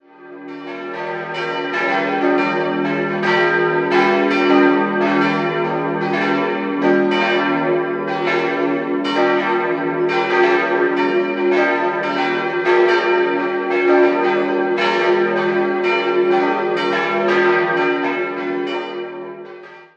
4-stimmiges ausgefülltes Es-Dur-Geläute: es'-f'-g'-b'
bell
Sehr kräftig klingendes Geläute mit etwas unsauberer Schlagtonlinie, bei dem allerdings die drei Hamm-Glocken mit der ansprechenden Pascolini-Glocke nicht besonders befriedigend harmonieren. Alle vier Glocken hängen im Südturm.